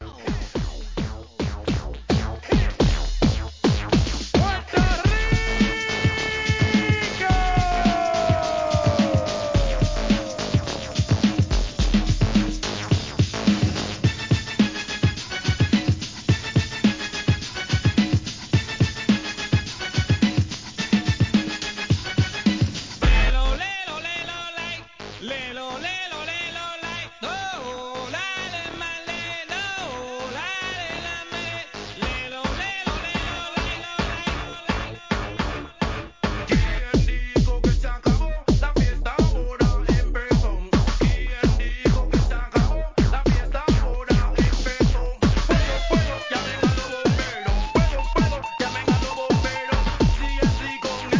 HIP HOP/R&B
1997年、ノリノリのラティーノ節!! 2枚組REMIXES!!